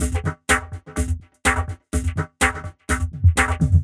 tx_perc_125_hosepipe2.wav